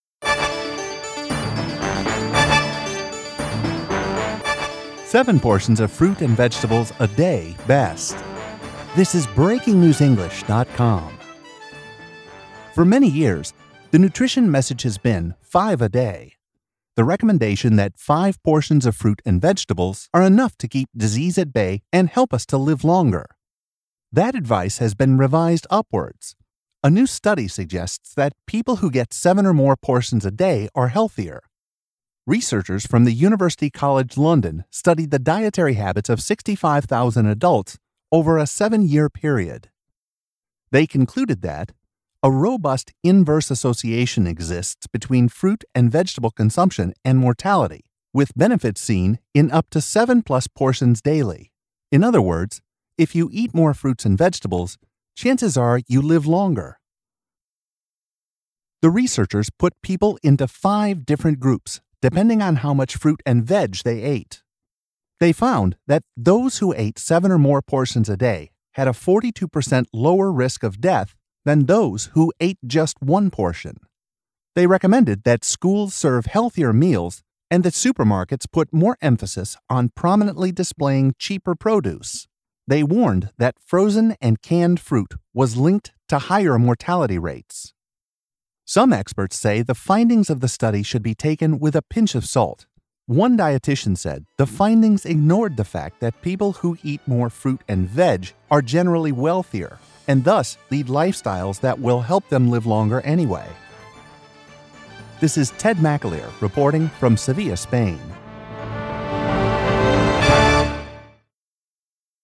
British speaker